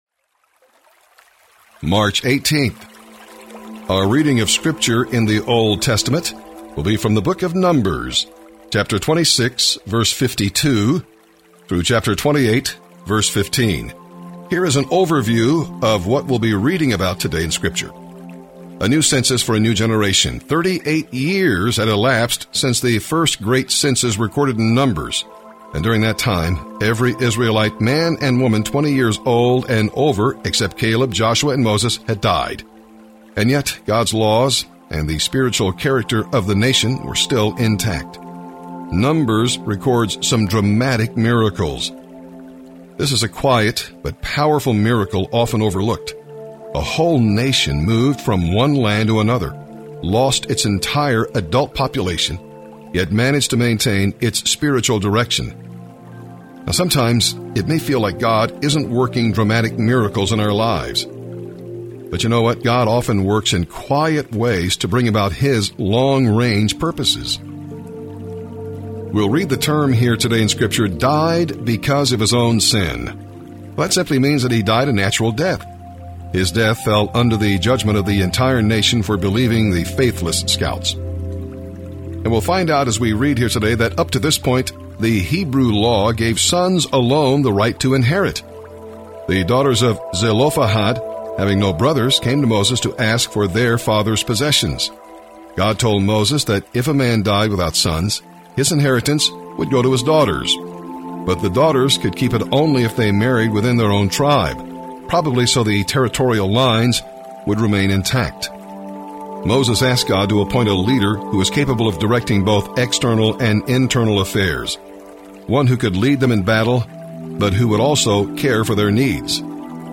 March 18th Bible in a Year Readings